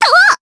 Annette-Vox_Attack3_jp.wav